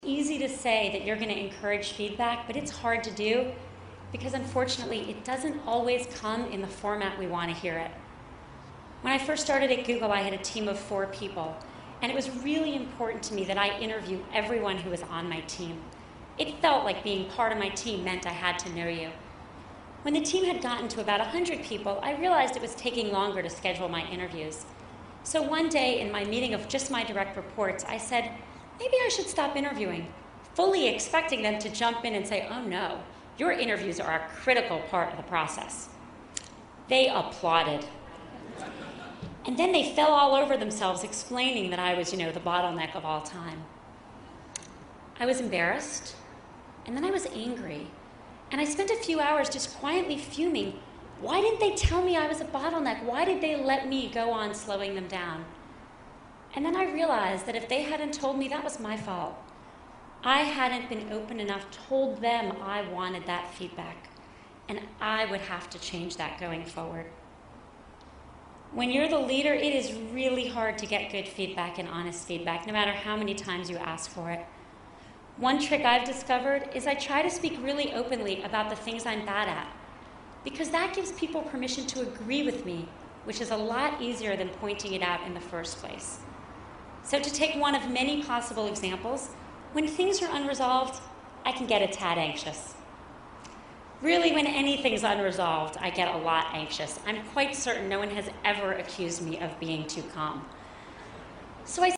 公众人物毕业演讲第184期:桑德伯格2012哈佛商学院(9) 听力文件下载—在线英语听力室